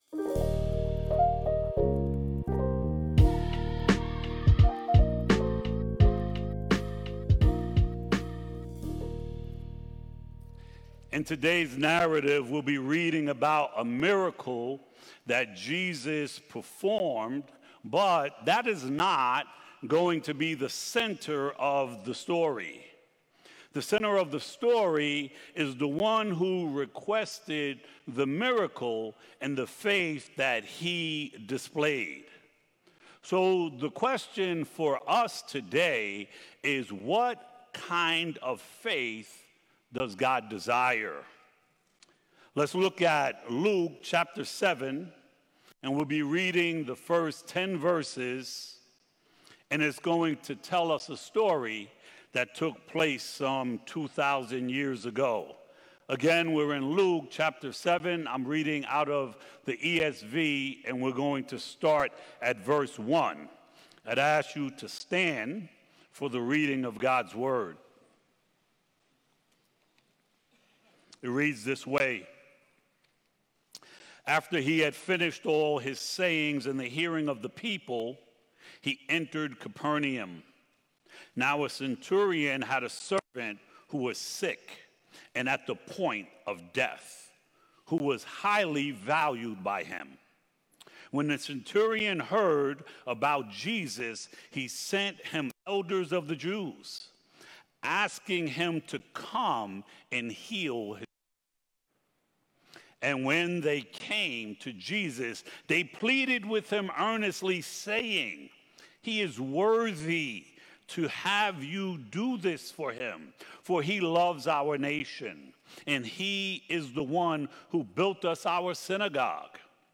Sermons from Light of the World Church in Minisink Hills